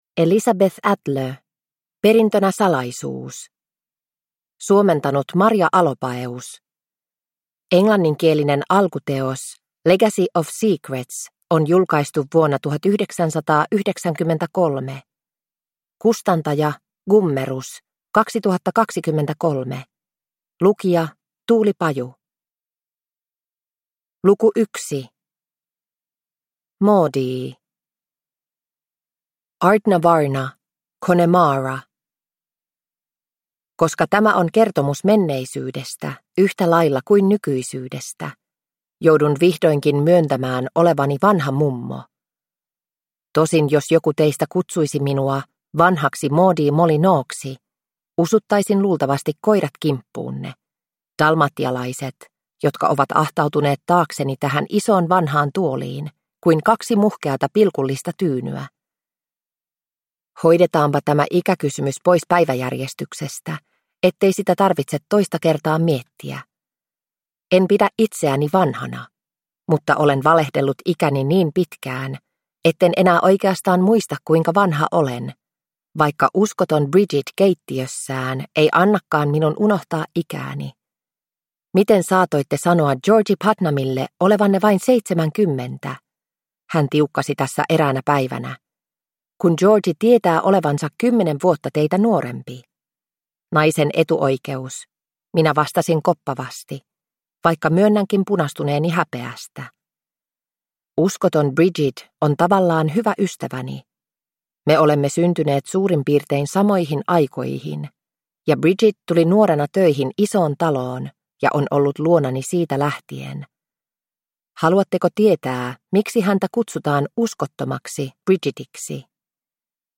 Perintönä salaisuus – Ljudbok – Laddas ner